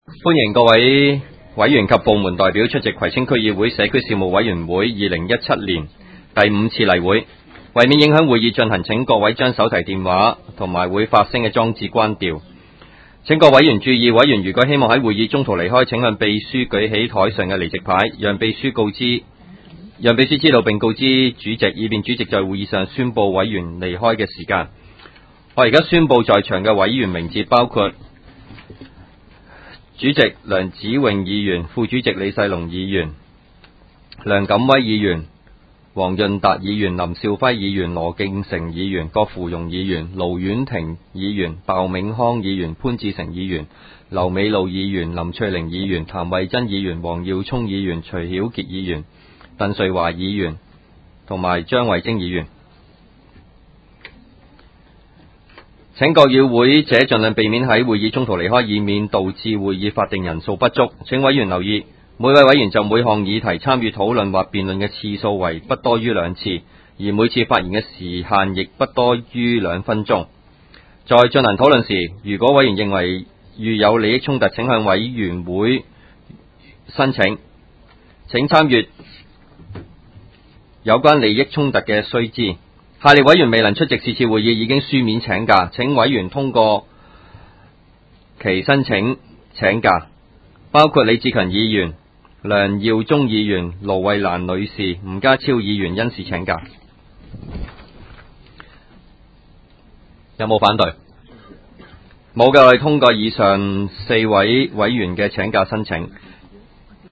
委员会会议的录音记录
开会词